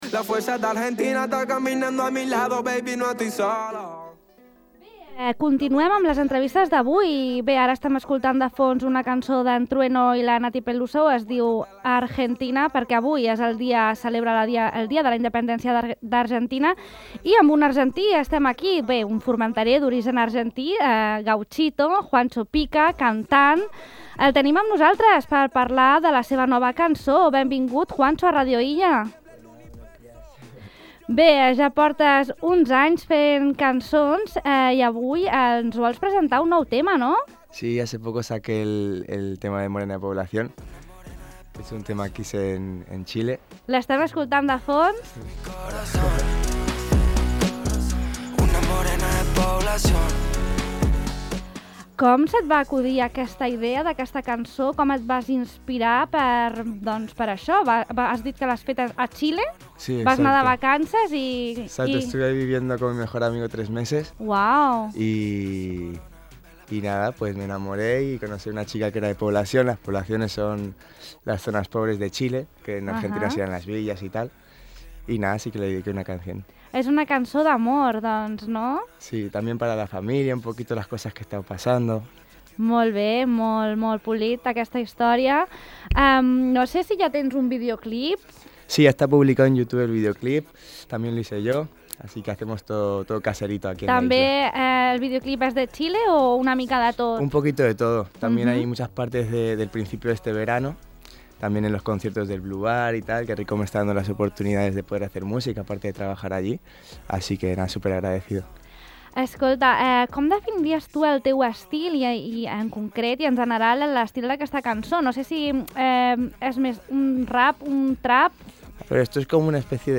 Compartim amb vosaltres l’entrevista sencera i el videoclip de ‘Morena de Población’: